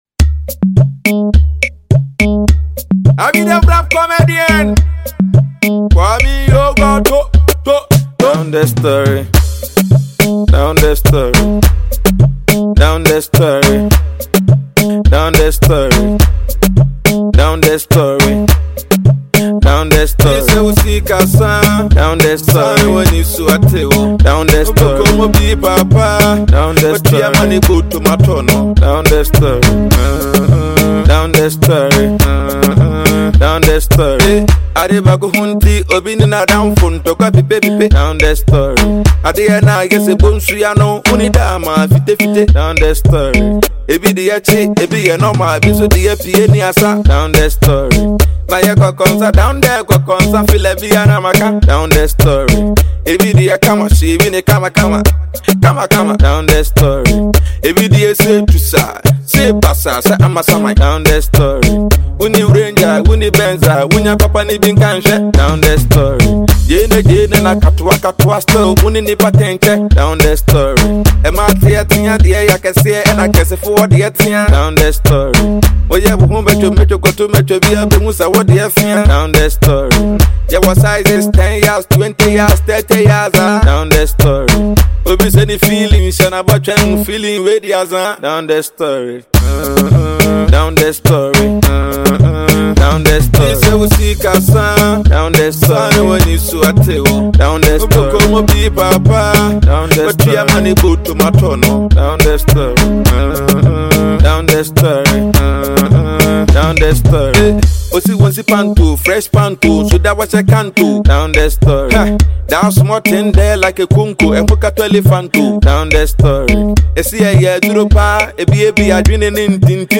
Ghanaian hard bars rapper
the rap comedian